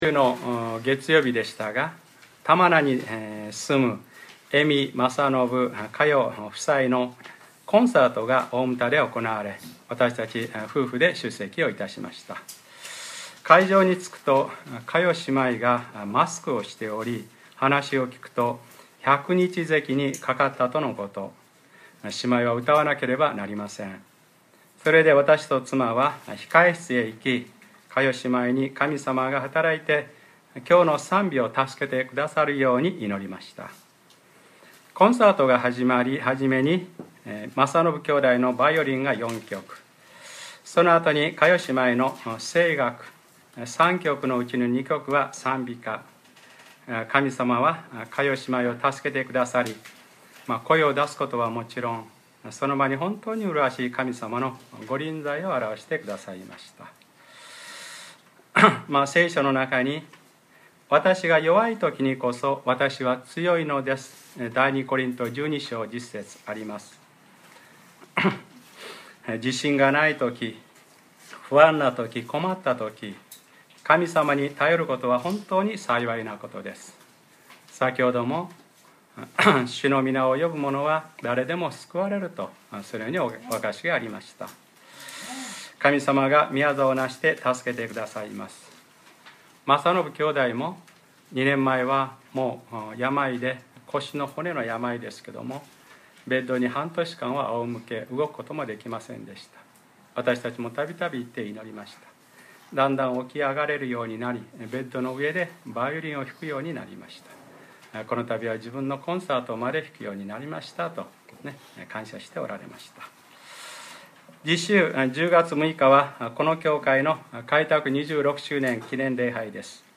2013年9月29日(日）礼拝説教 『黙示録ｰ２３：最後の７つの災害の序曲』